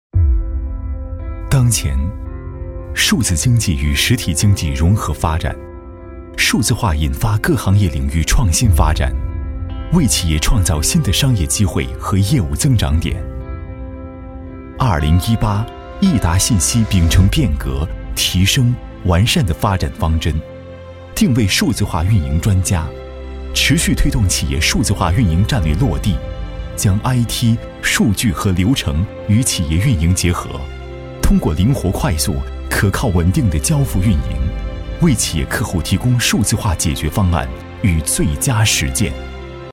广告配音
标签： 浑厚
配音风格： 稳重 讲述 大气 激情 厚重 浑厚